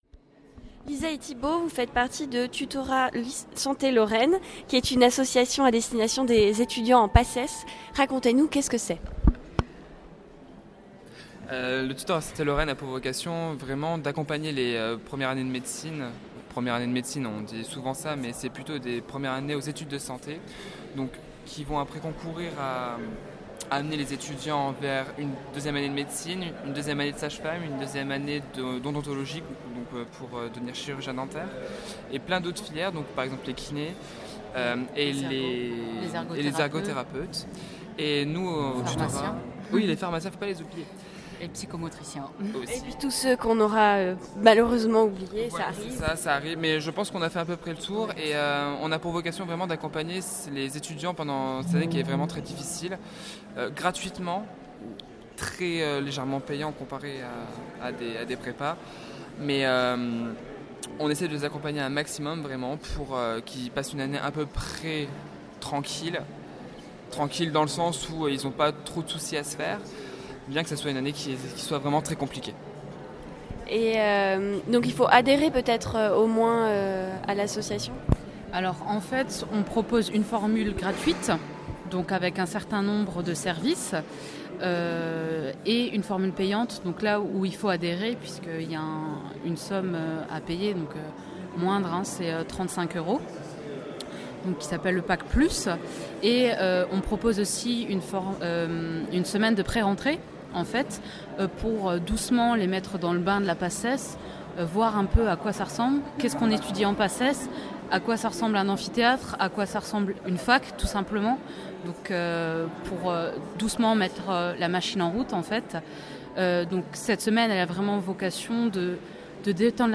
Les officiels de la vie étudiantes (transports, logements, restauration, sécu, job, etc.) et quelques associations nancéiennes se sont réunies pour un salon à l’Hôtel de ville de Nancy le samedi 7 juillet 2018.